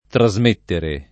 trasmettere [ tra @ m % ttere ] v.;